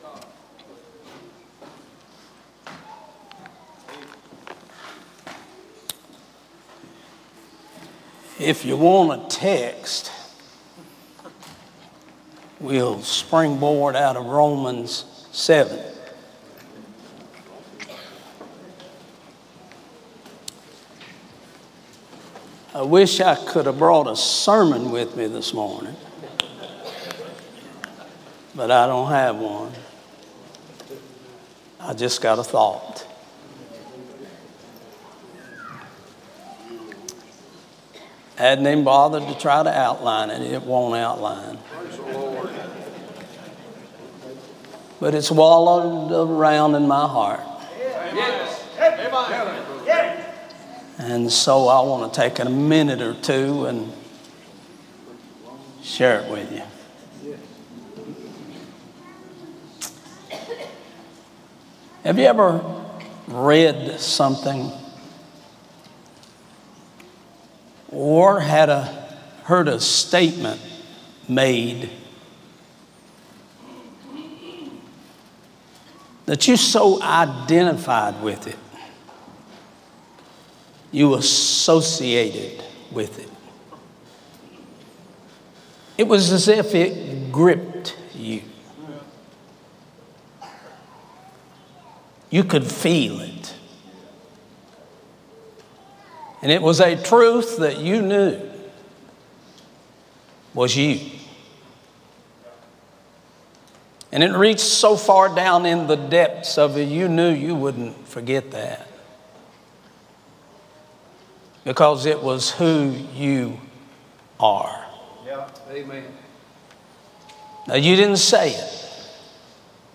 A sermon preached Tuesday morning during our Spring Jubilee, on March 26, 2024.